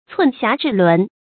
寸轄制輪 注音： ㄘㄨㄣˋ ㄒㄧㄚˊ ㄓㄧˋ ㄌㄨㄣˊ 讀音讀法： 意思解釋： 喻控制事物的關鍵雖小而極重要。